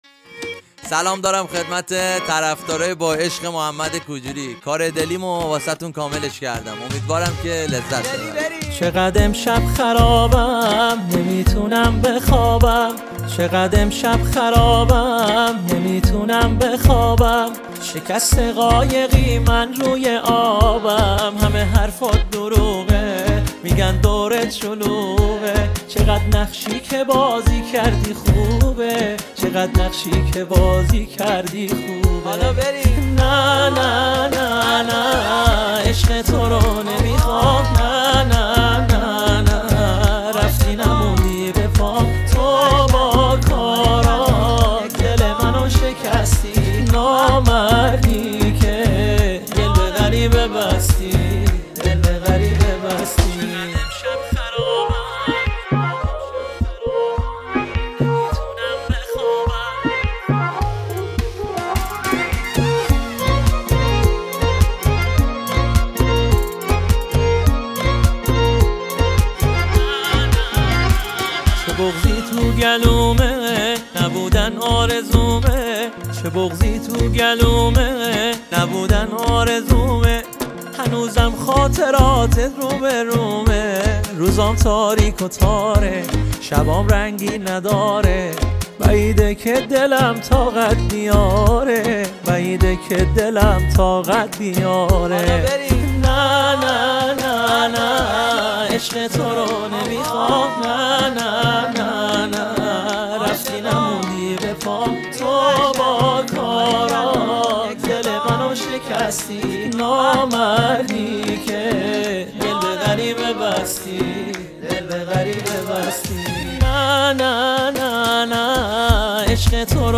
آهنگ محلی
دانلود آهنگ شاد